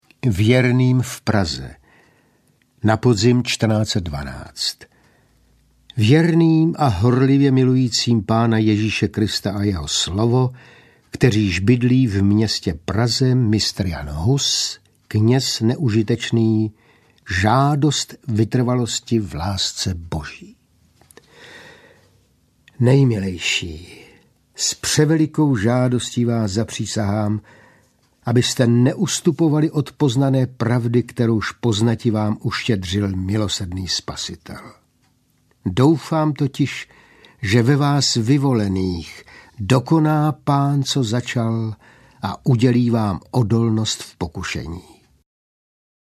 Ukázka z knihy
listy-m-jana-husa-audiokniha